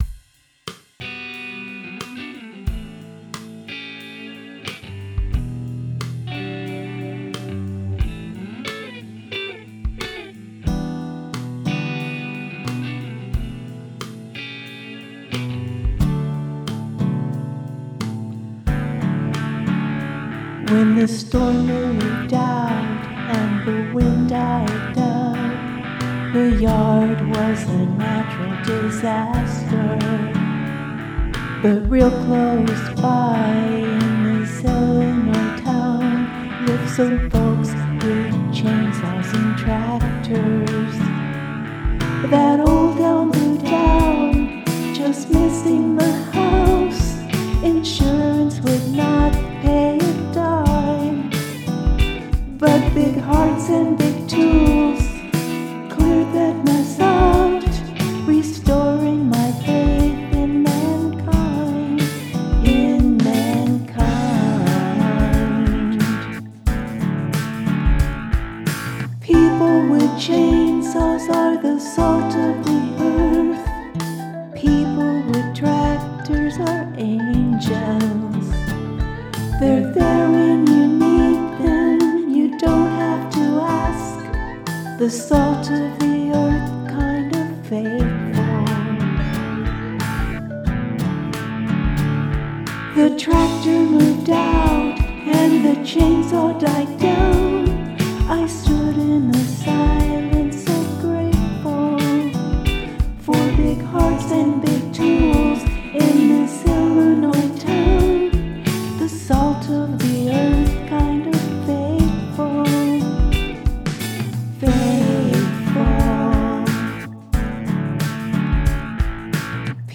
A country song.
I arranged and recorded it on Garage Band.
I played an acoustic guitar patch on the piano and found some electric loops for filler riffs.